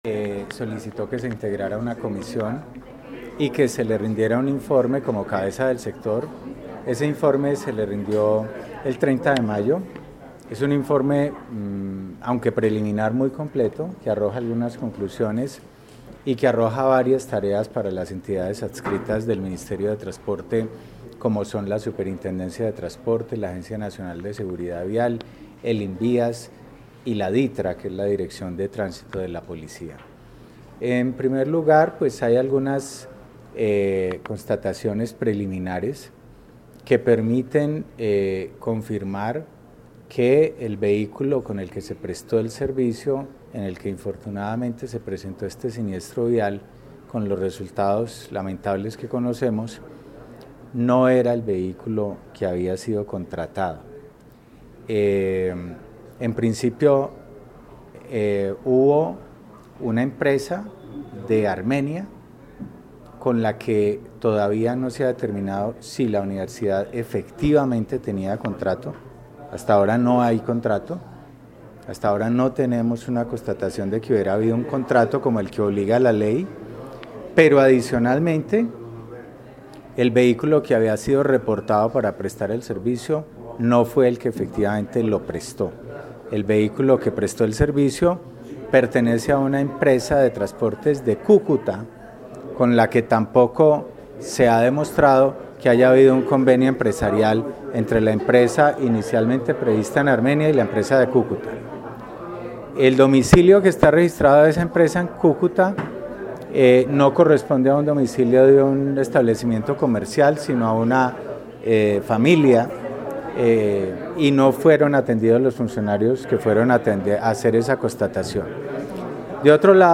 Francisco Taborda, jefe de la oficina Juridica de Mintransporte